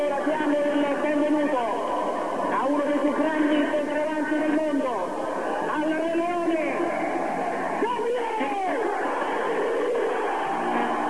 擴音器內向在場球迷正式介紹巴迪斯圖達 (Wav, 239kb)